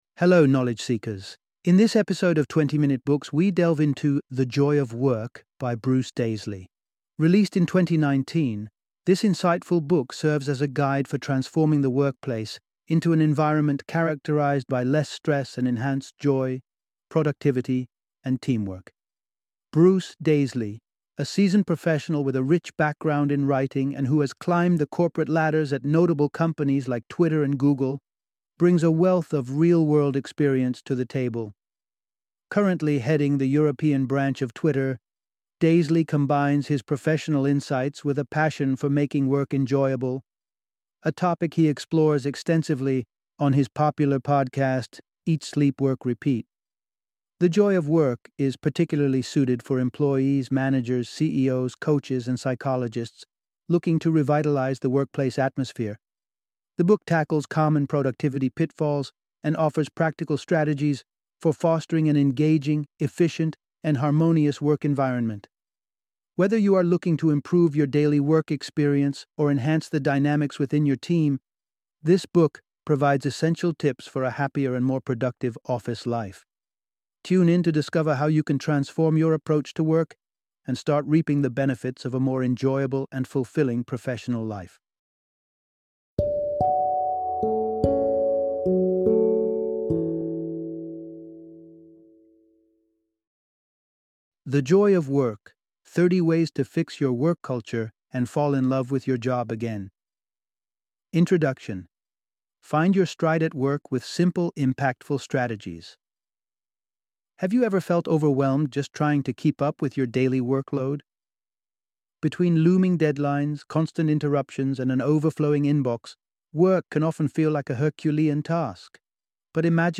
The Joy of Work - Audiobook Summary